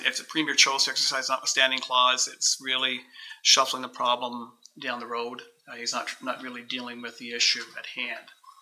Mayor Shawn Pankow shared his thoughts.